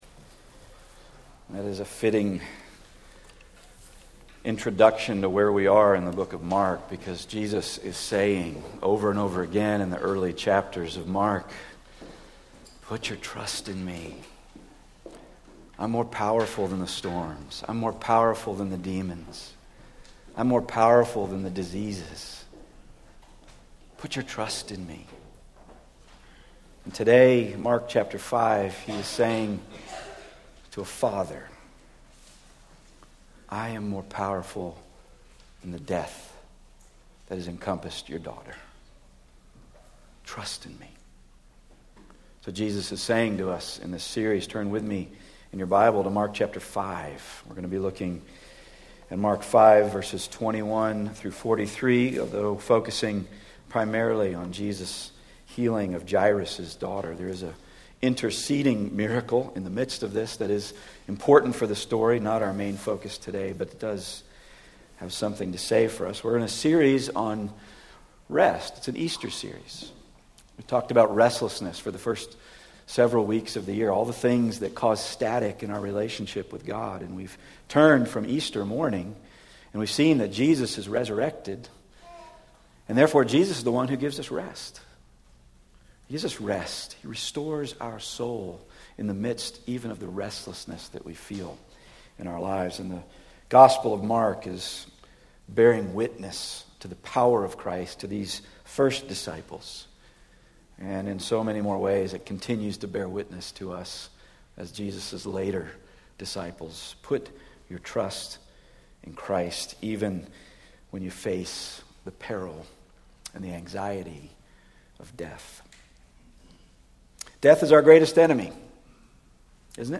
35-43 Service Type: Weekly Sunday